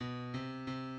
key Bm